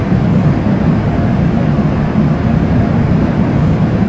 320roll.wav